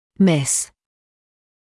[mɪs][мис]отсутствовать; пропускать, не замечать